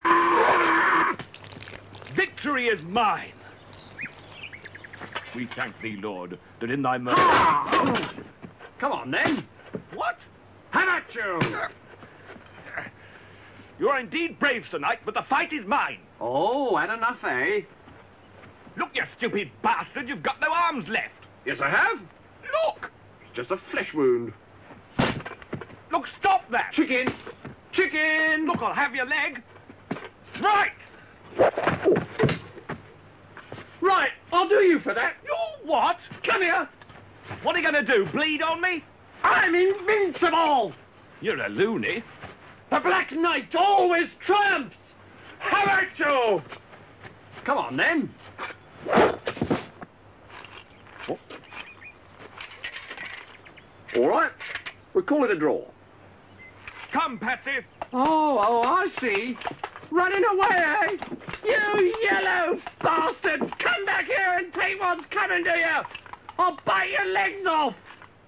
Here are three NEWLY RECORDED MP3 files of clips from the very funny Black Knight scene. (I took them straight from the DVD!